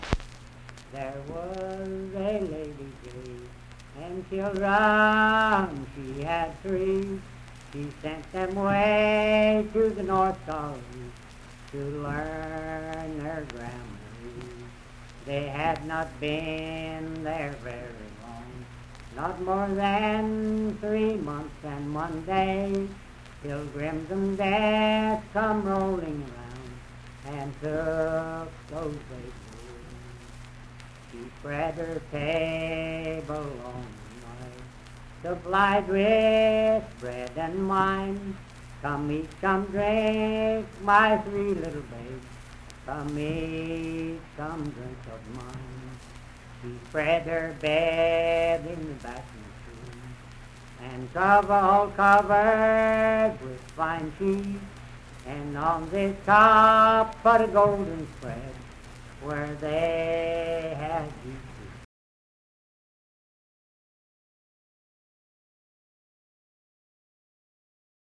Unaccompanied vocal music and folktales
Verse-refrain 4(4).
Voice (sung)
Parkersburg (W. Va.), Wood County (W. Va.)